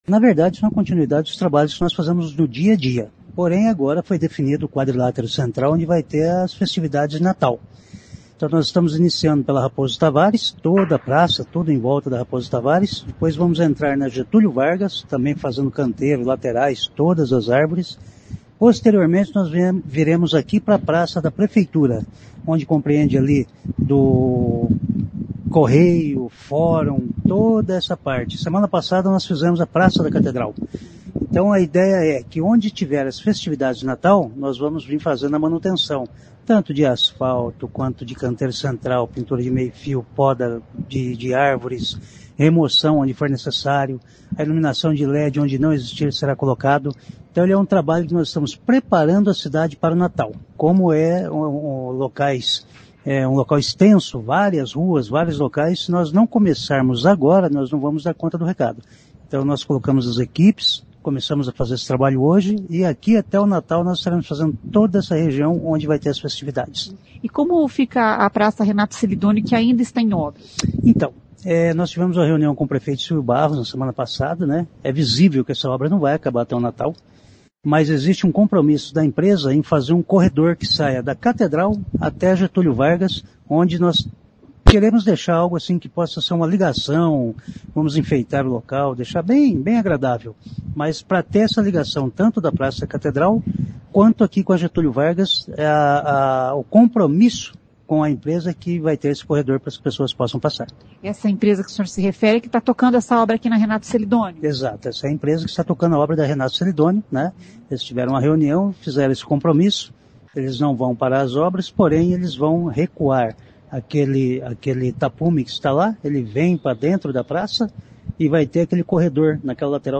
Um corredor será aberto entre a Avenida XV de Novembro e a Avenida Tiradentes, para que os visitantes possam atravessar a Praça Renato Celidônio que está em obras. Ouça o que diz o secretário de Infraestrutura Vagner Mussio.